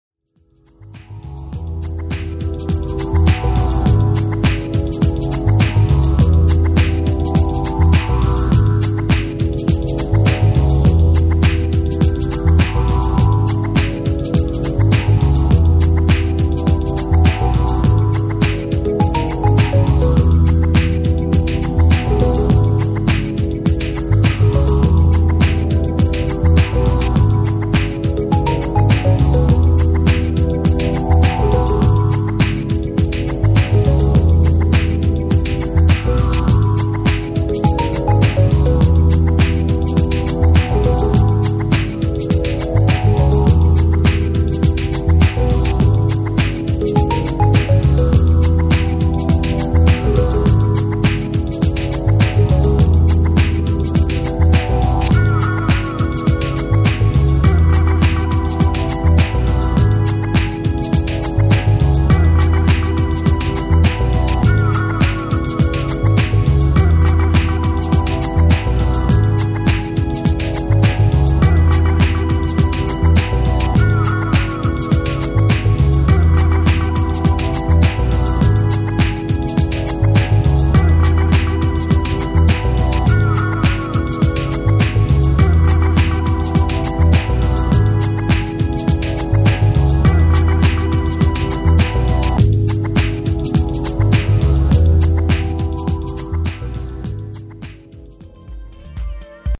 ジャジーで上品、ディープで内省的なサウンド・メイキングが好きな人にはたまらない珠玉の4トラック！